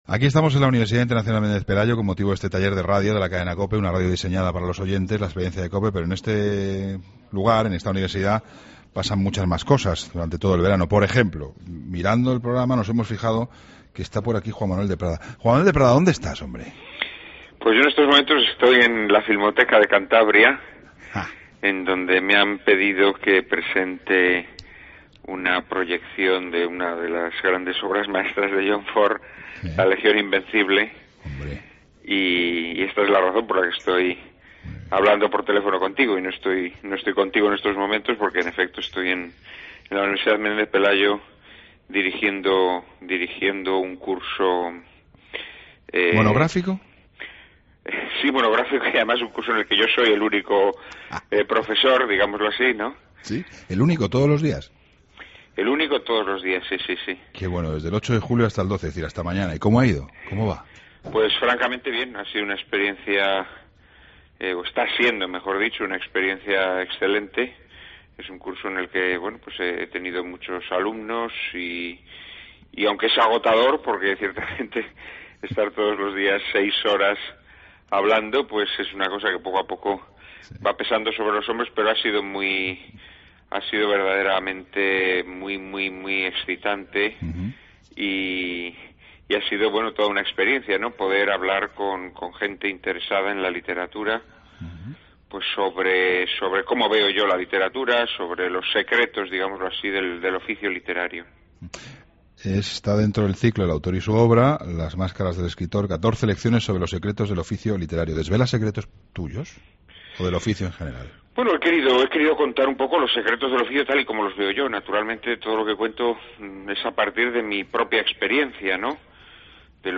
AUDIO: Entrevista a Juan Manuel de Prada.